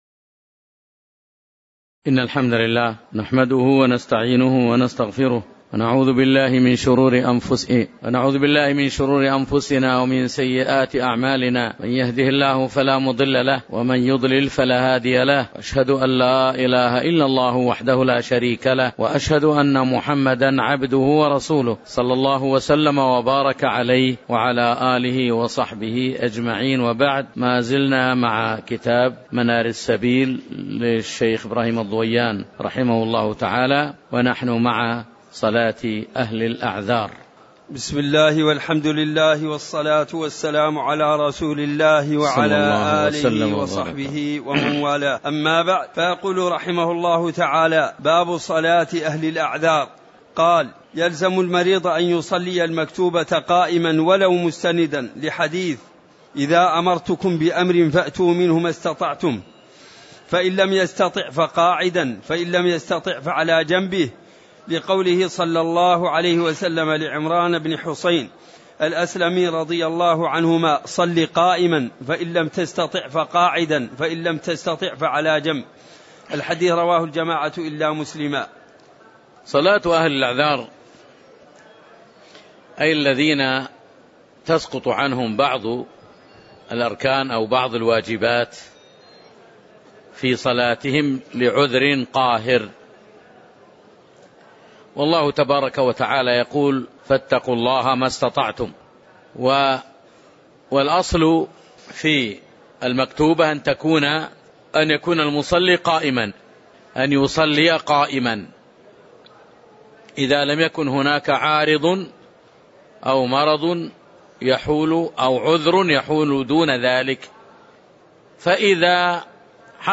تاريخ النشر ٢٢ صفر ١٤٣٩ هـ المكان: المسجد النبوي الشيخ